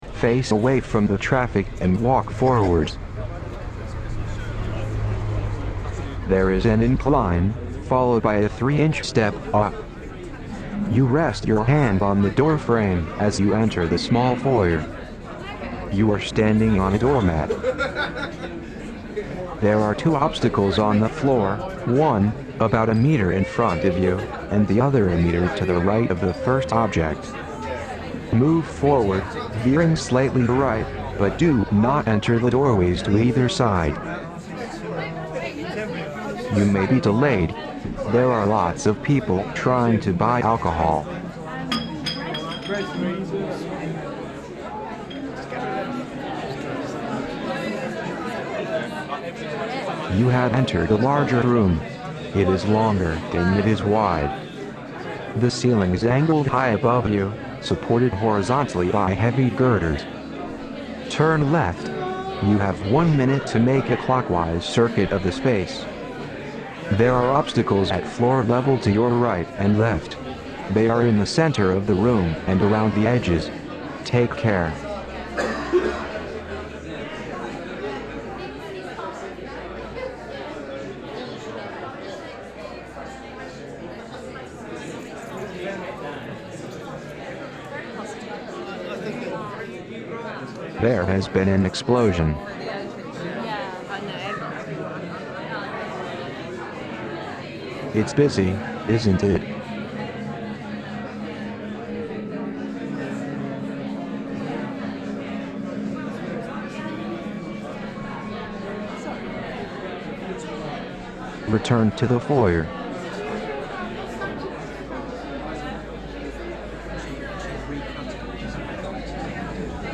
I avoided any descriptions of the visual qualities of the space because I wanted the piece to refer to audio guides for the blind, where objects and spaces are treated as physical things rather than observable things. The piece consists of a binaural recording made during the private view, layered with a Text Edit voice guiding the participant around the building.